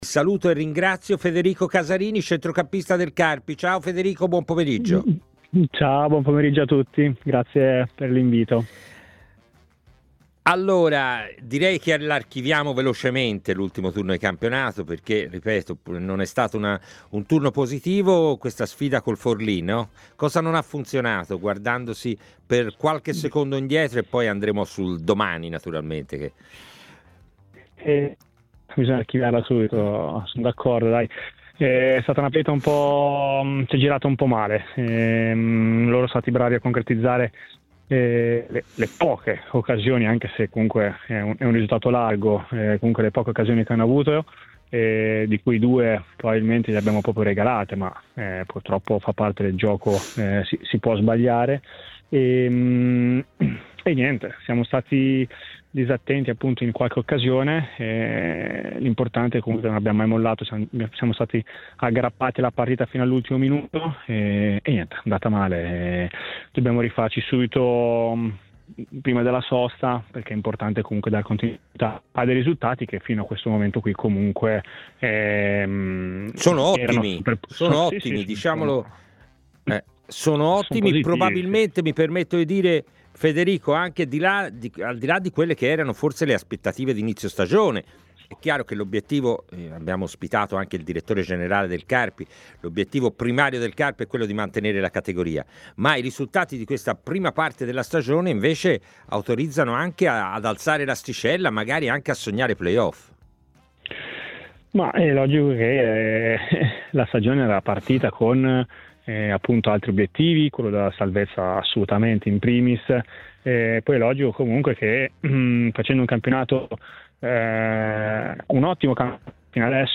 è intervenuto nel corso della trasmissione "A Tutta C", in onda su TMW Radio e sul canale Il61 del digitale terrestre.